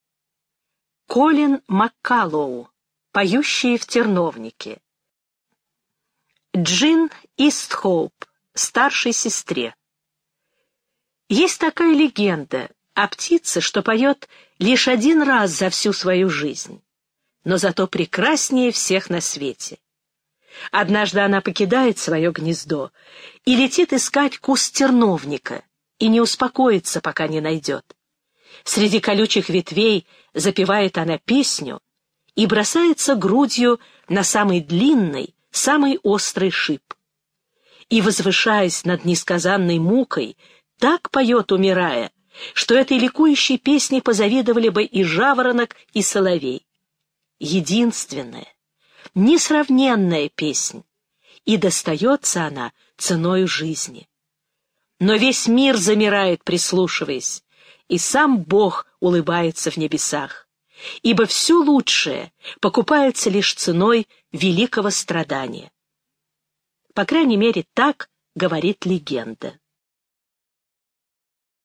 Аудиокнига Поющие в терновнике
Качество озвучивания весьма высокое.